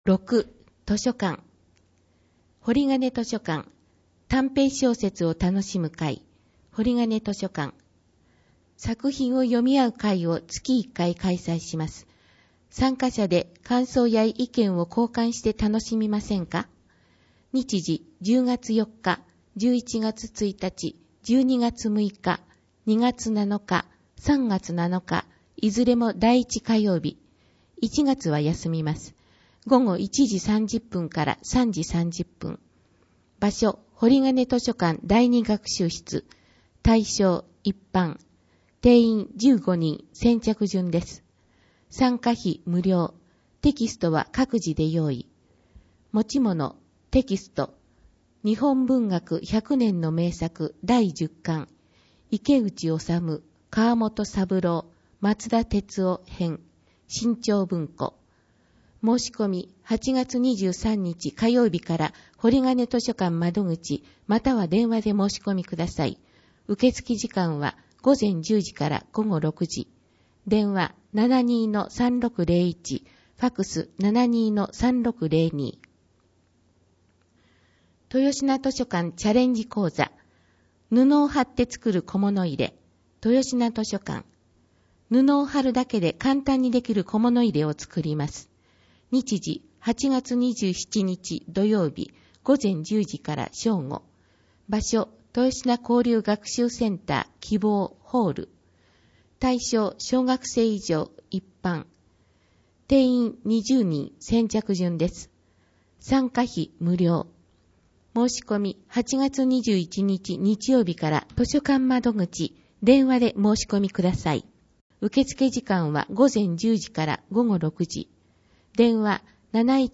「広報あづみの」を音声でご利用いただけます。この録音図書は、安曇野市中央図書館が制作しています。